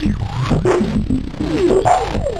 Index of /90_sSampleCDs/Spectrasonic Distorted Reality 2/Partition A/04 90-99 BPM